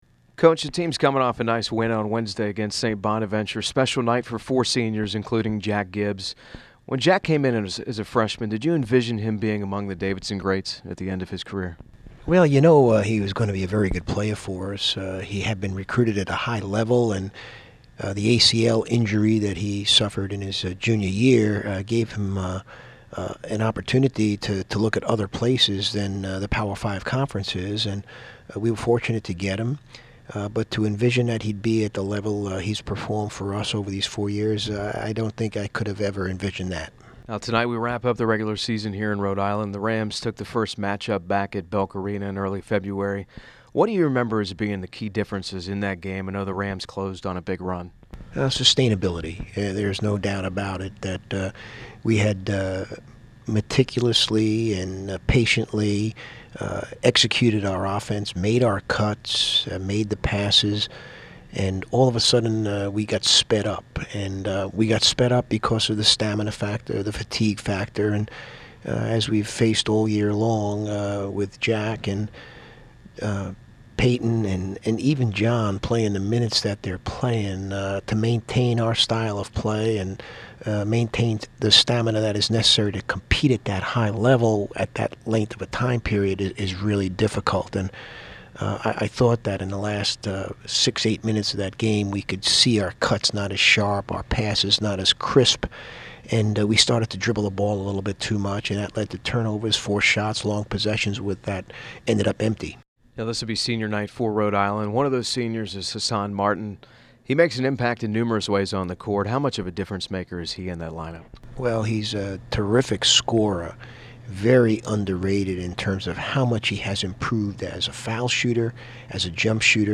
Pregame Radio Interview